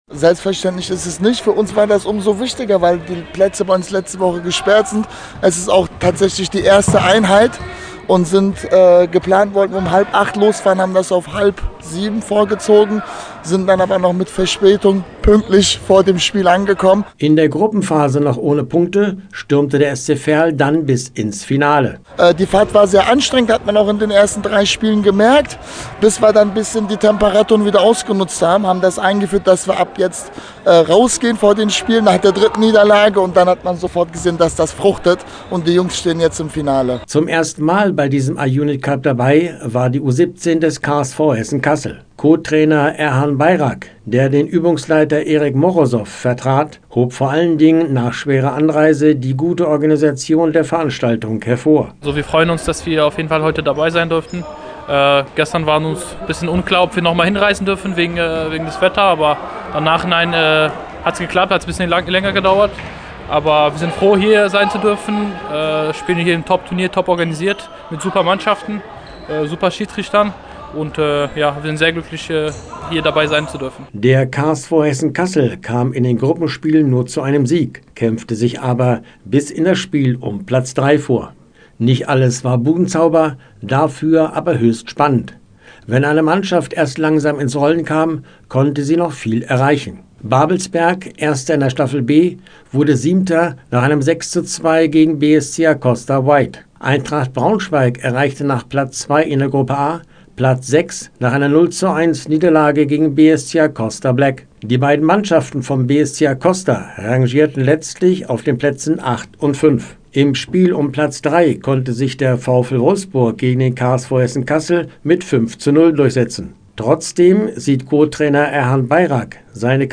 Spannenden Fußball gab es auch in der Dennis-Schröder-Sporthalle am Franzschen Feld in Braunschweig. Dort fand am Samstag das traditionsreiche B-Jugendturnier des BSC Acosta statt.